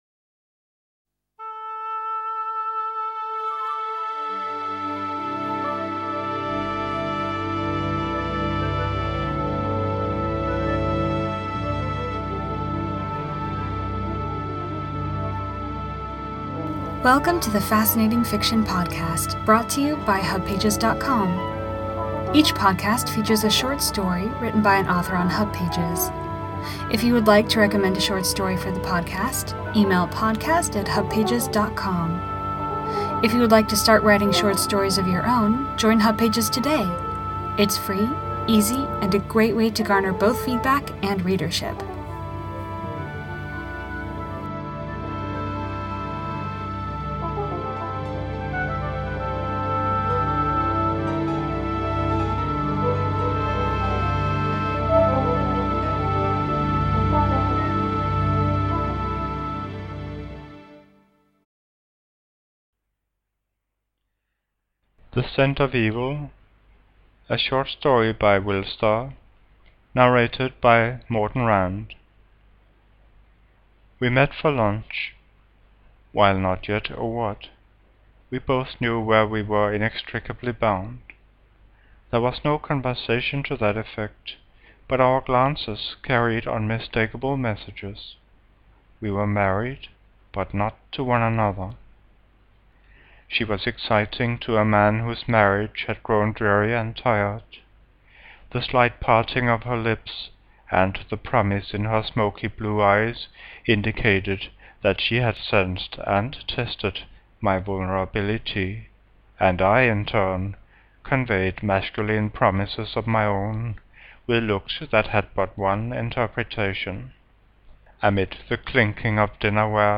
This is the first Fascinating Fiction episode with a short story narrated by another Hubber.